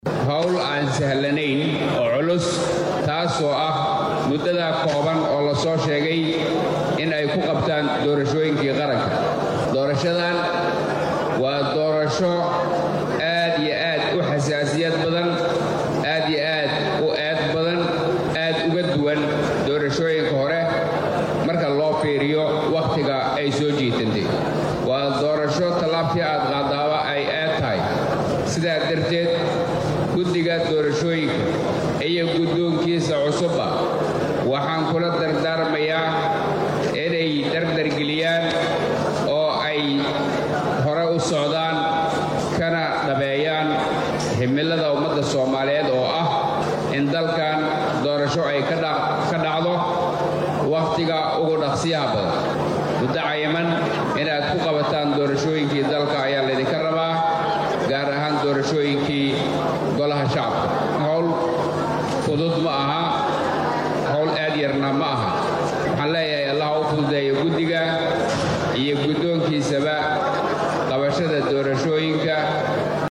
Dhanka kale guddoomiyaha gobolka Banaadir ahna duuqa Muqdisho Cumar Maxamud Cumar Filish oo dhankiisa hadlay ayaa ku guddiga ugu baaqay in maalmaha kooban ee ay haystaan ku soo dhameystiraan howlaha doorashooyinka ee golaha shacabka Soomaaliya.